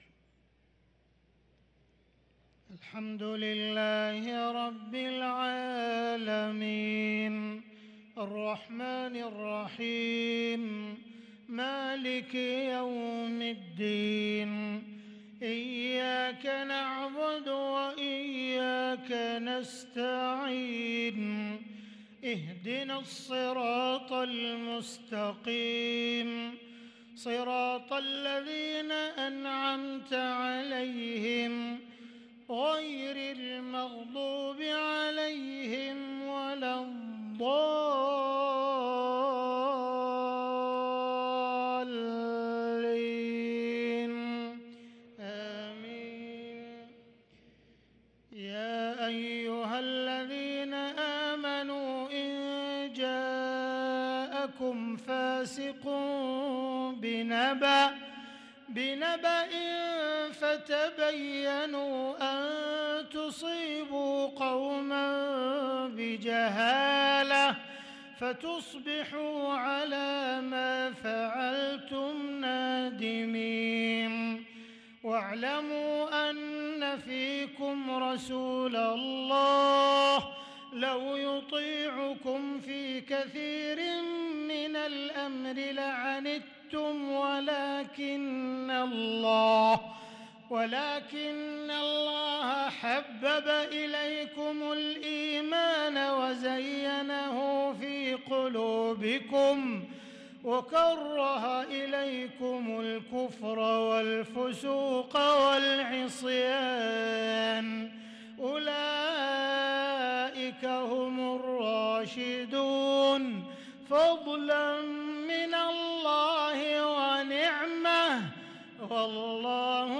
صلاة العشاء للقارئ عبدالرحمن السديس 7 ربيع الآخر 1444 هـ
تِلَاوَات الْحَرَمَيْن .